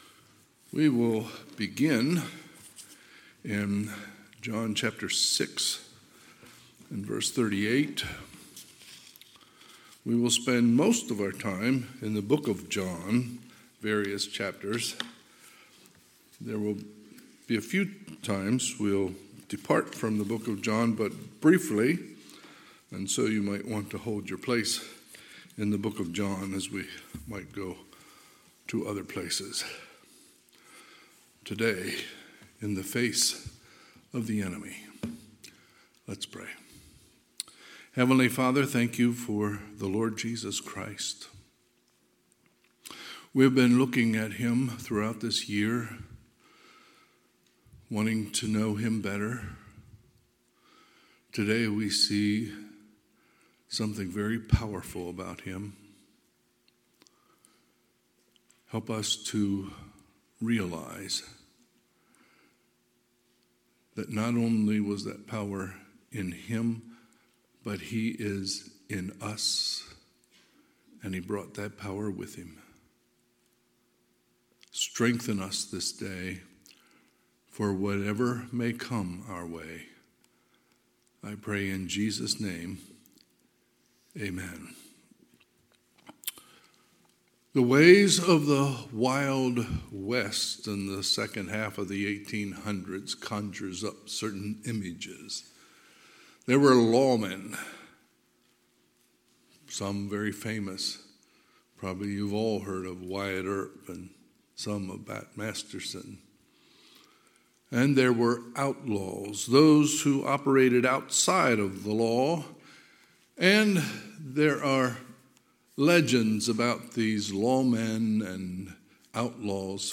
Sunday, October 15, 2023 – Sunday AM
Sermons